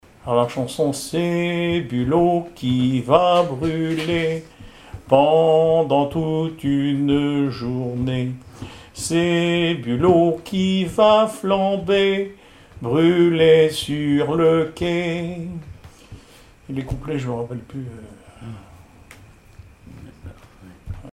carnaval, mardi-gras
Témoignages sur le cycle calendaire et des extraits de chansons maritimes
Pièce musicale inédite